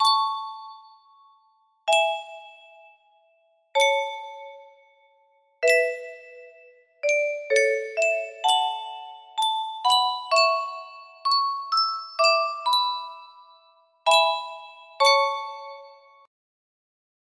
Yunsheng Music Box - Beethoven Symphony No. 9 3rd Movement 4036 music box melody
Full range 60